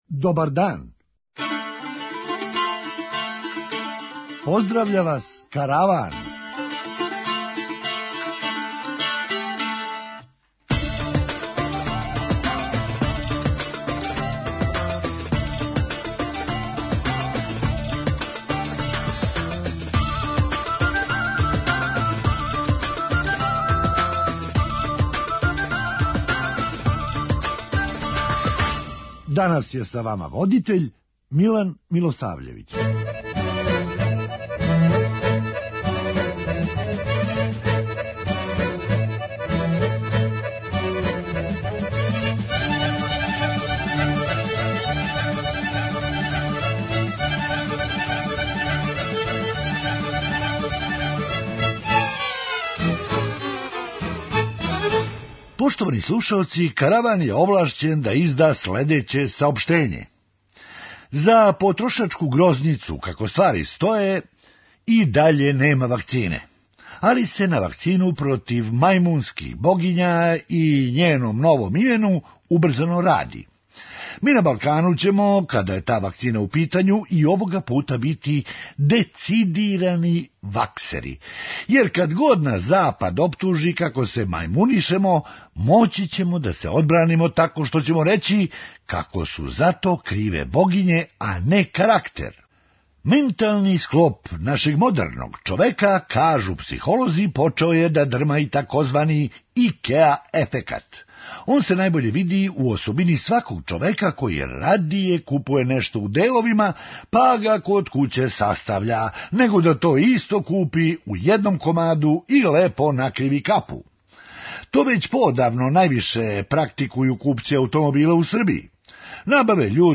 Хумористичка емисија
Али, како смо незванично сазнали, хакерима је љубазно речено да се опет јаве - за шест месеци! преузми : 6.03 MB Караван Autor: Забавна редакција Радио Бeограда 1 Караван се креће ка својој дестинацији већ више од 50 година, увек добро натоварен актуелним хумором и изворним народним песмама.